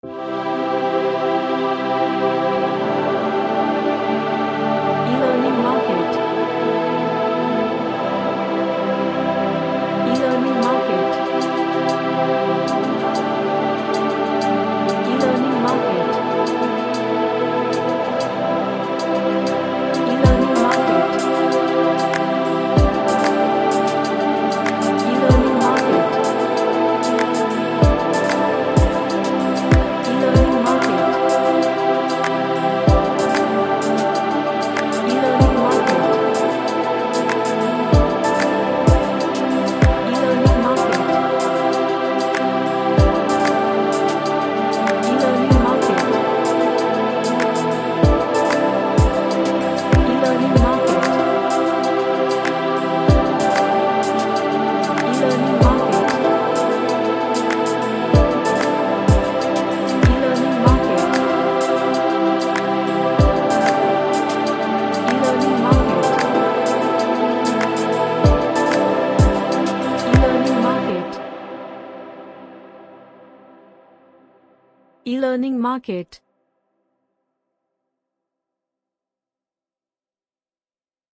A Ambient track with lots of pads.
Relaxation / Meditation